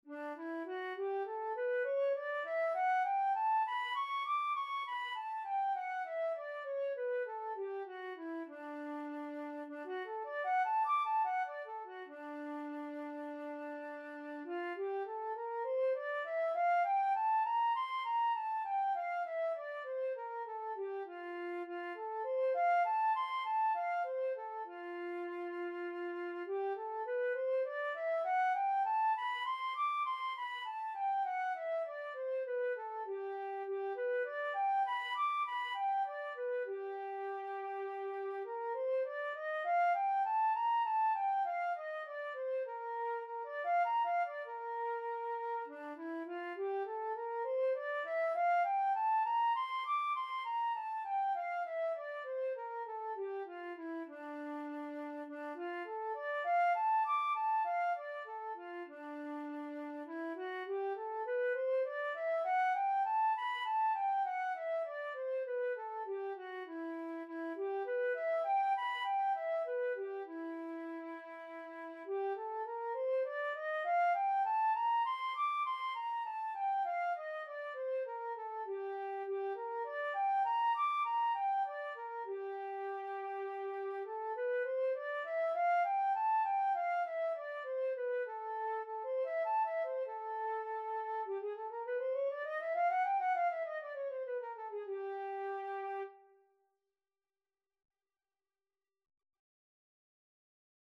Flute scales and arpeggios - Grade 3
D major (Sounding Pitch) (View more D major Music for Flute )
D5-D7
Flute  (View more Beginners Flute Music)
flute_scale_grade3.mp3